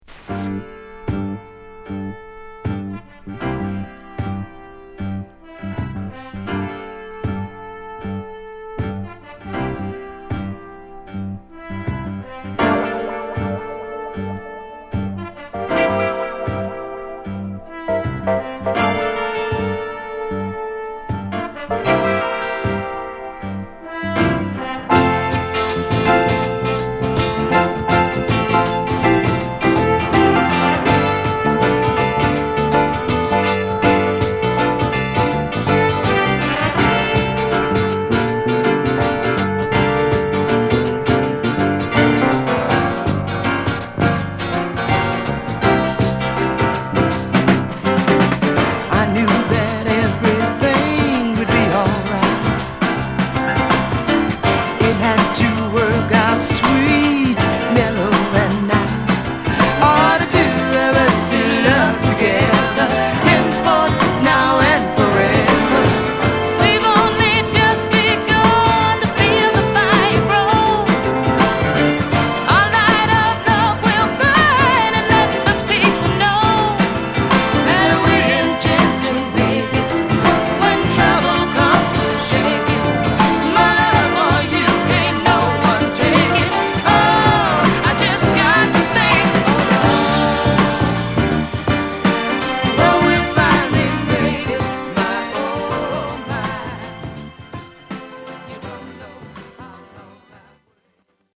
チェンバロなどのストリングスを聴いても分かる通り